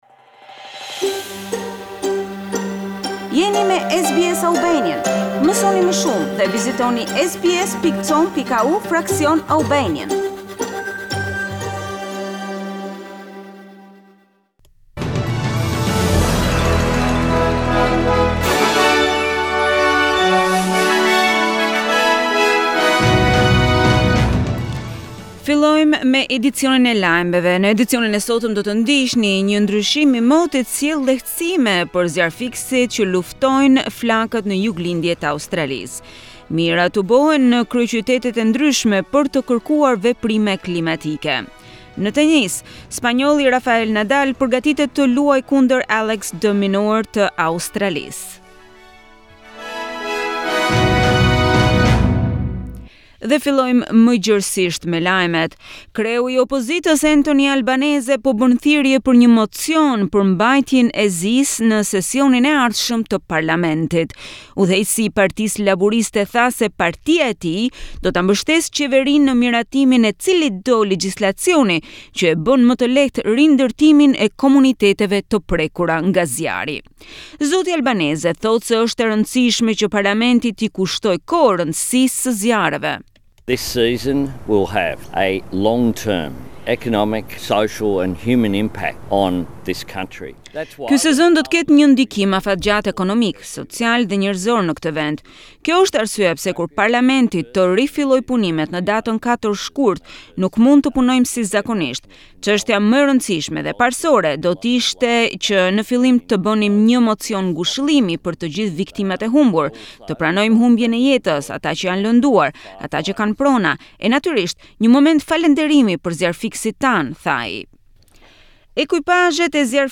SBS News Bulletin 11 January 2020